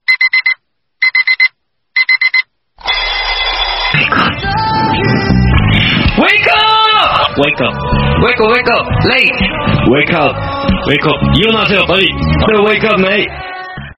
Kategori: Nada dering
Jika kamu suka nada alarm lagu Korea yang imut